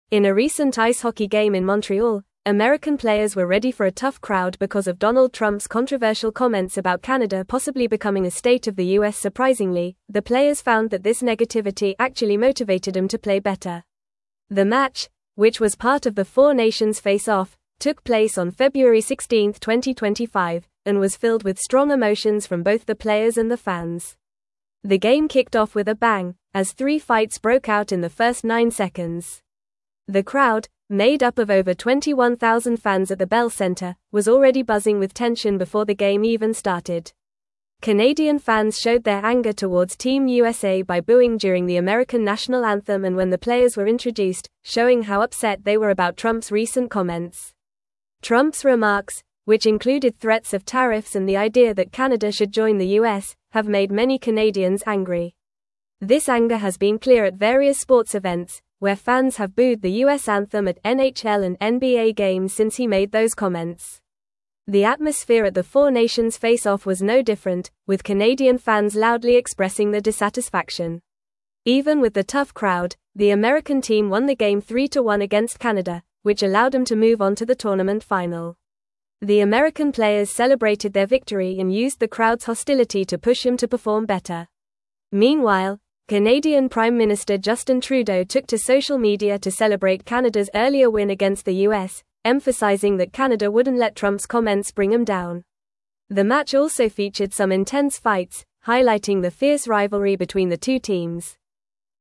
Fast
English-Newsroom-Upper-Intermediate-FAST-Reading-Intense-Rivalry-Ignites-During-4-Nations-Face-Off.mp3